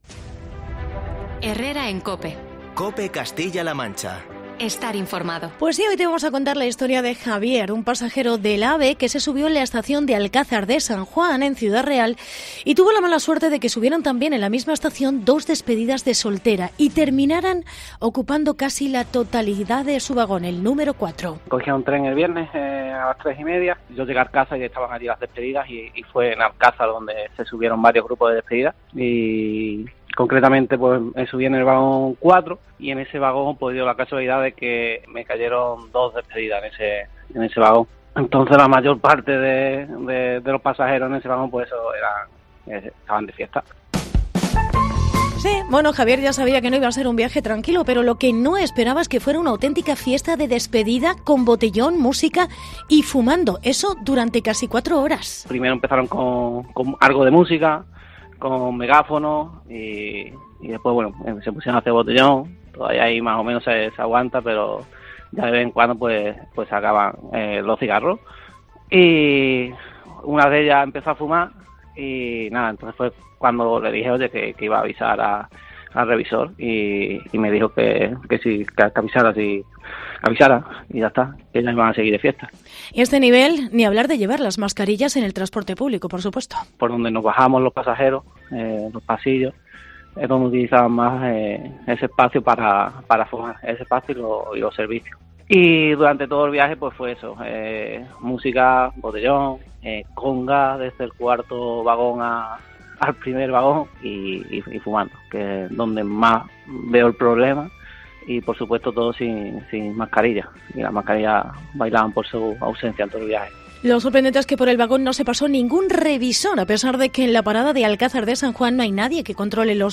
Reportaje despedida de solteras en el AVE